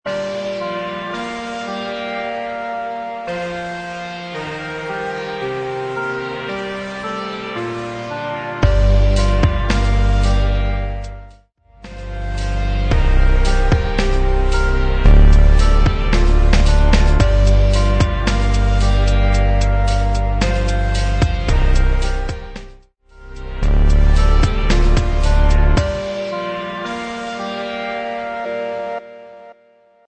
112 BPM
Electronic